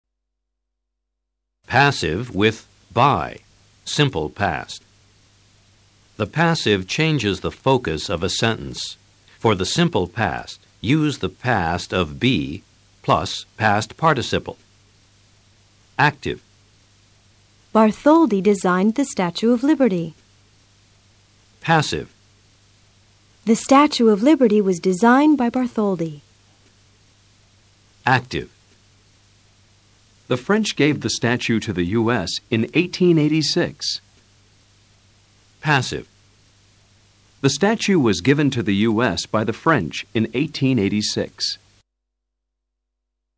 Escucha atentamente a los profesores leyendo oraciones en VOZ ACTIVA y PASIVA.